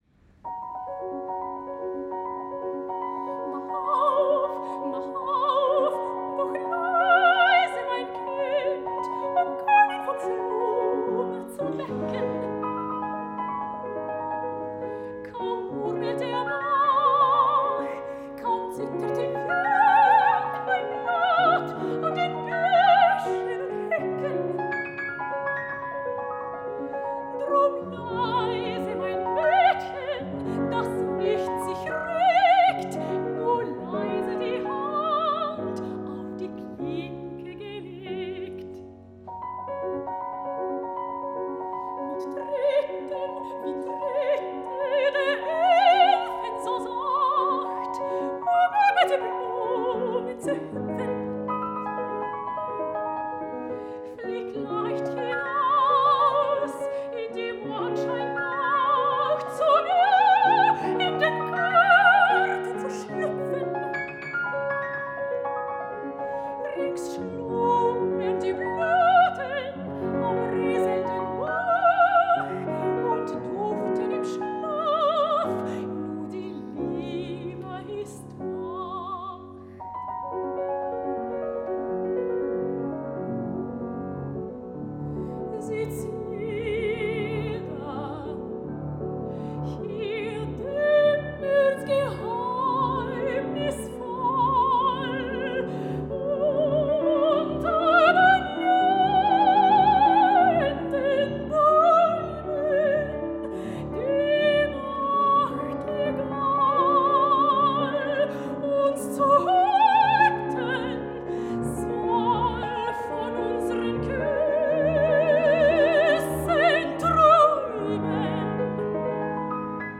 German melodies
Richard Strauss – Ständchen (Piano